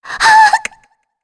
Shea-Vox_Damage_kr_03.wav